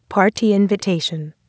party_invite.wav